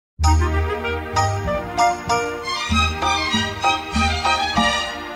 奥尔夫音乐 - 活泼、欢快的音乐2.mp3